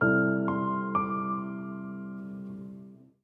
Звуки смартфона Самсунг